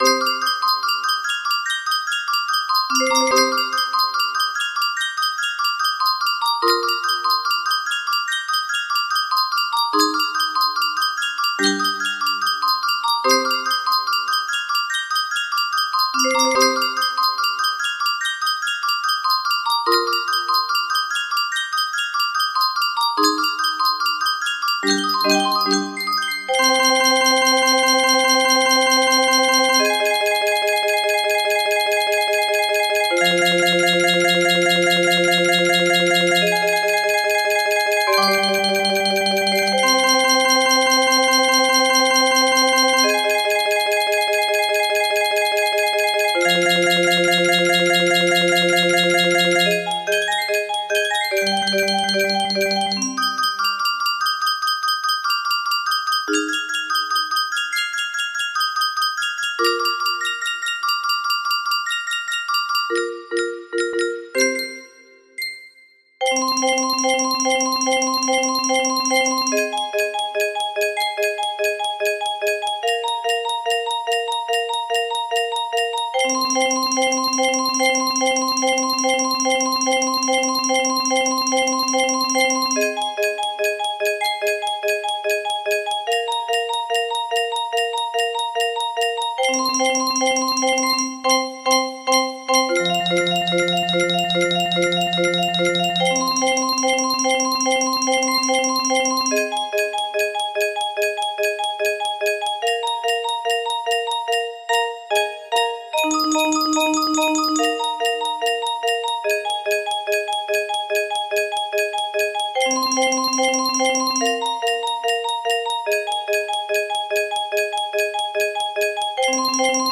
Arranged For Music Box